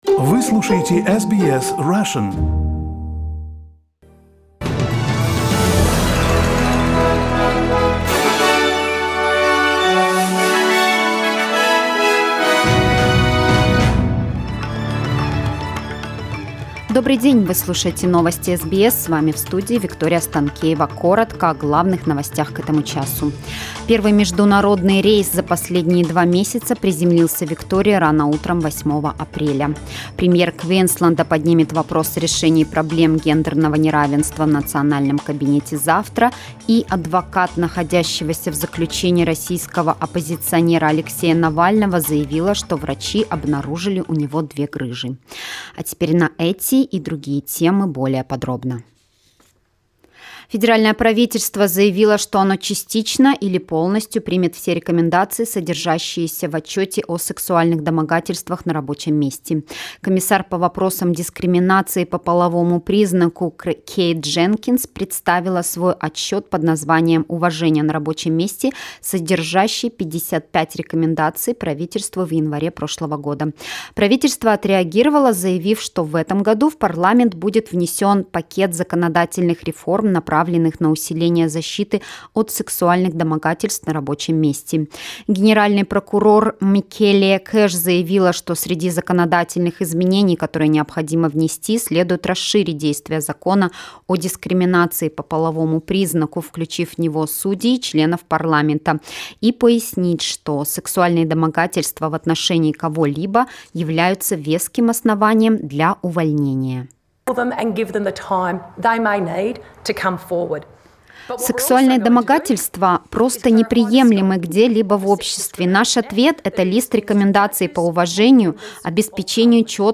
Новостной выпуск за 8 апреля